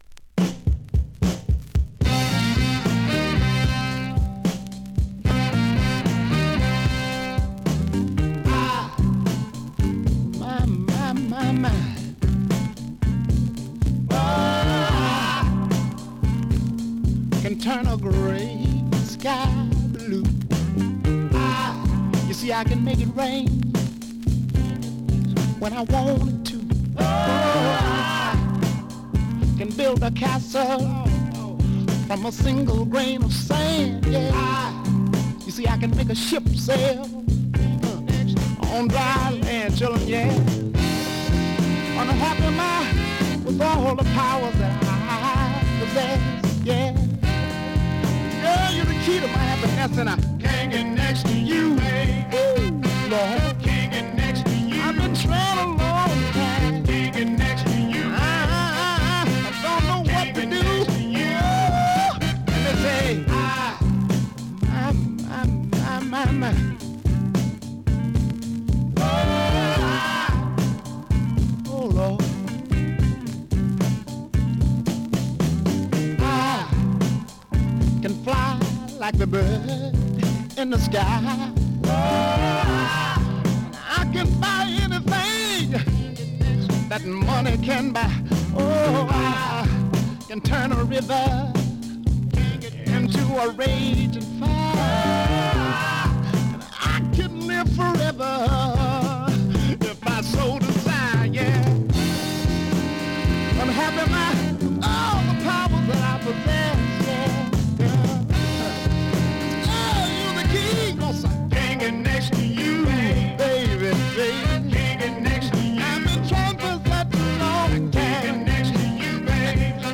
現物の試聴（両面すべて録音時間６分２０秒）できます。